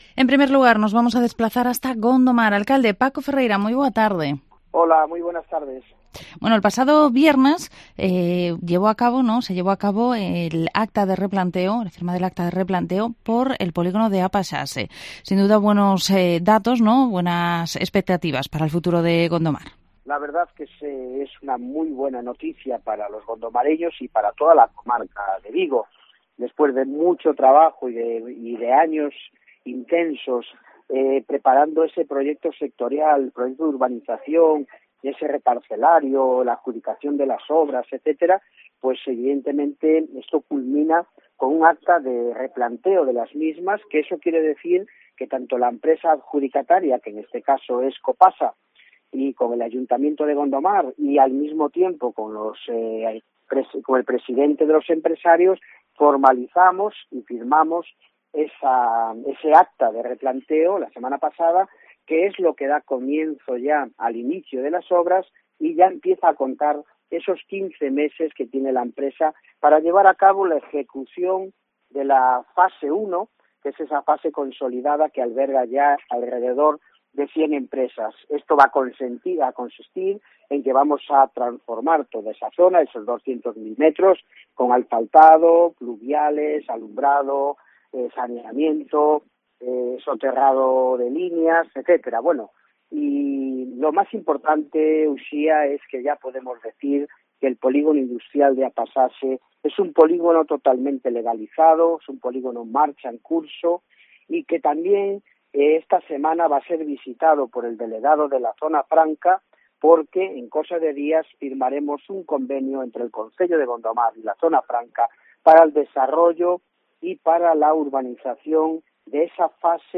Entrevista Alcalde de Gondomar, Paco Ferreira